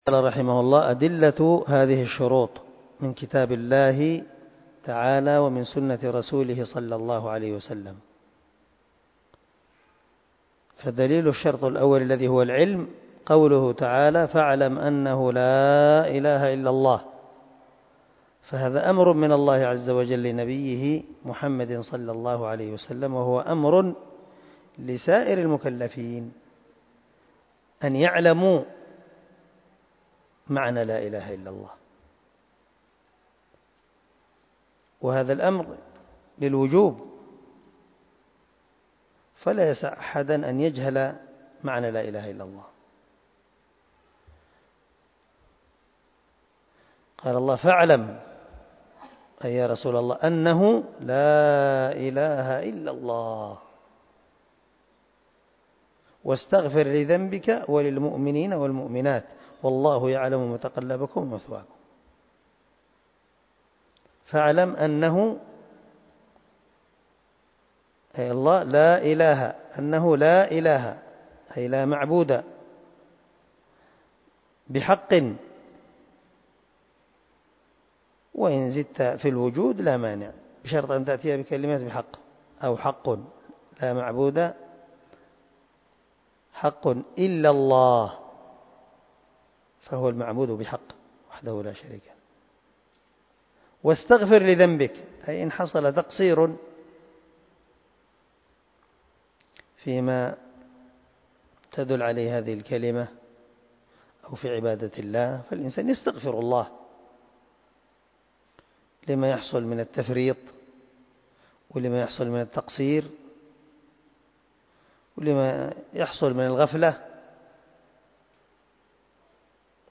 🔊الدرس 4 أدلة شروط لا اله الا الله وأولها العلم( من شرح الواجبات المتحتمات)